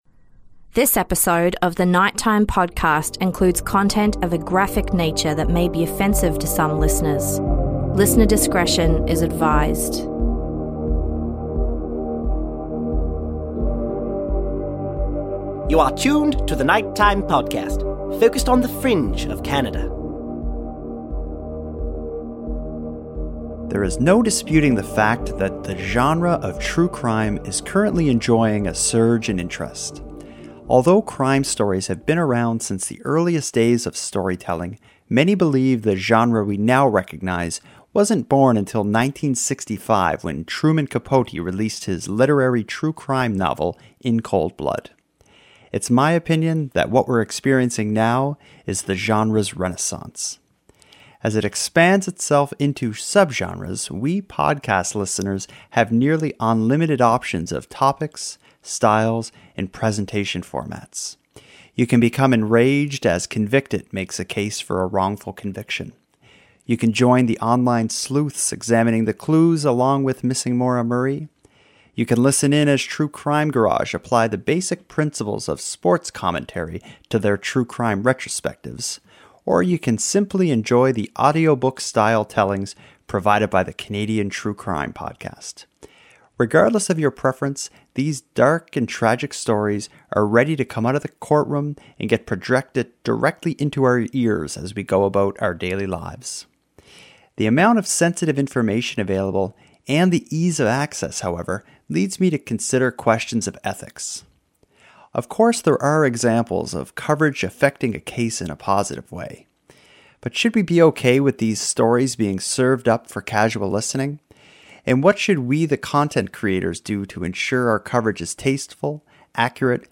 told via excerpts of my recent conversation with her neice